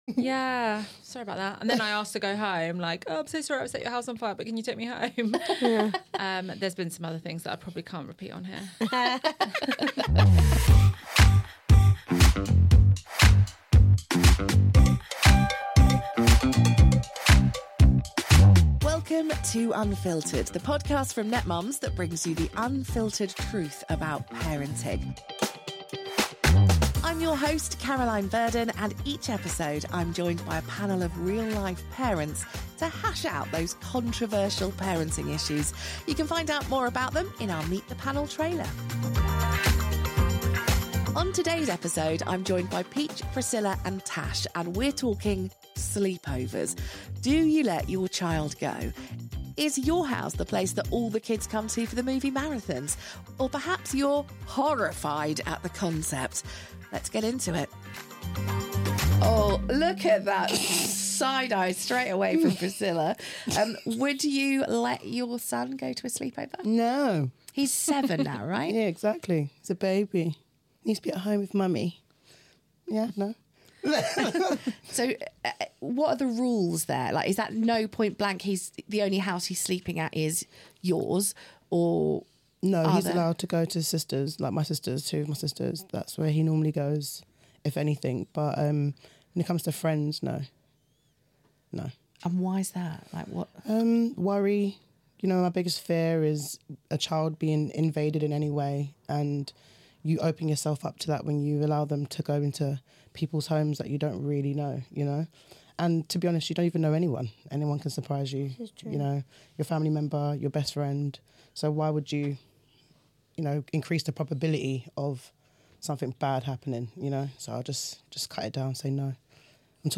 Tune in for a lively discussion that gets to the heart of these questions and more.